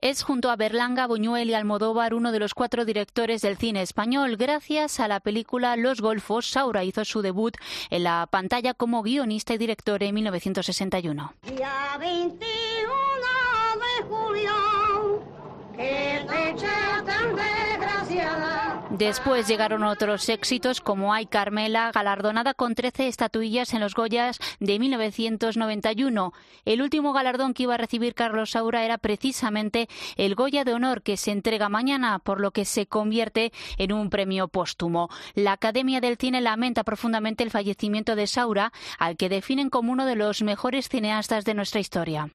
Te da más detalles la redactora de COPE